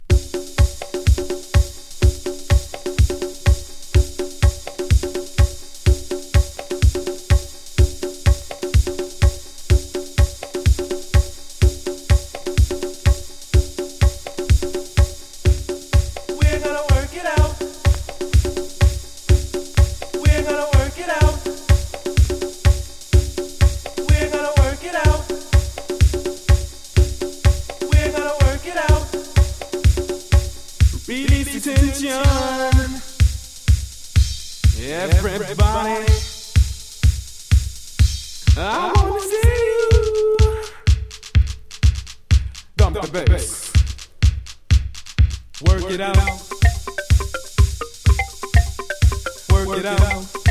テコテコ鳴るビートがクセになるシカゴサウンド！